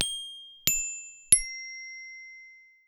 chime.wav